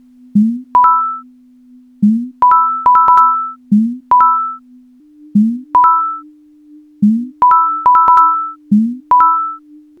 Category 🎮 Gaming
analog arcade coins jump videogame sound effect free sound royalty free Gaming